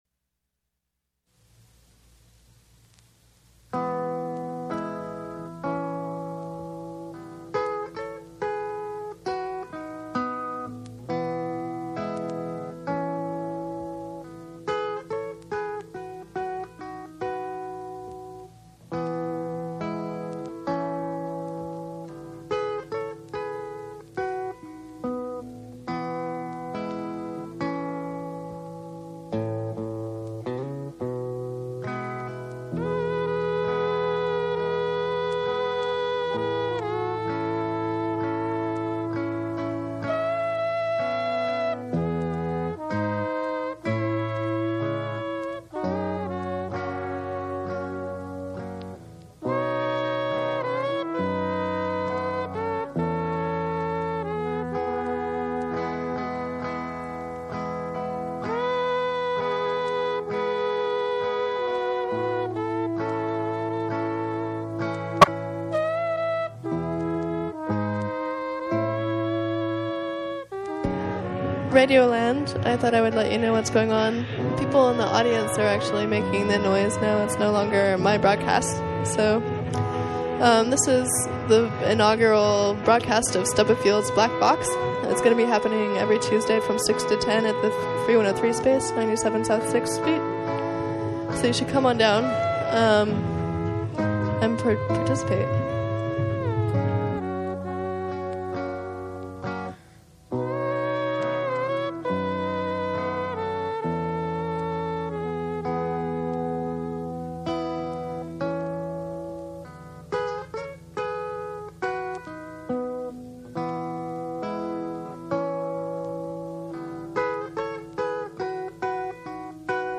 Recorded to CDR at the free103point9 Project Space and Gallery.